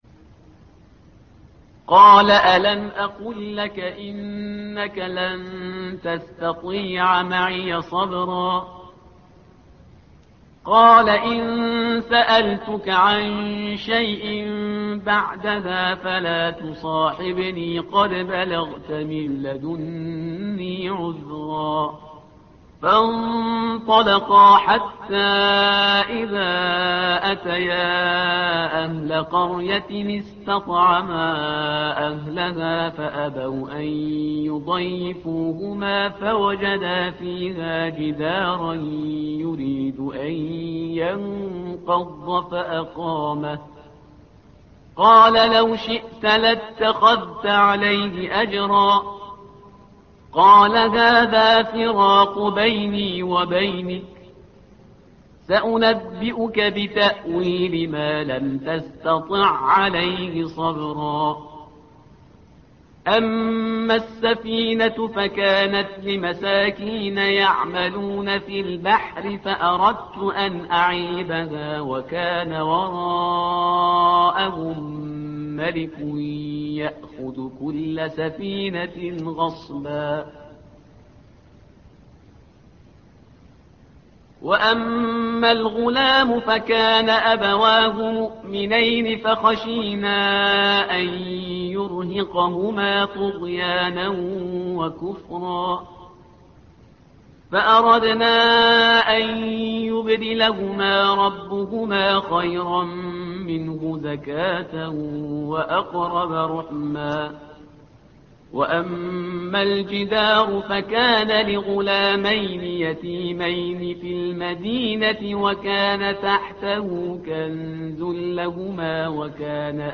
تحميل : الصفحة رقم 302 / القارئ شهريار برهيزكار / القرآن الكريم / موقع يا حسين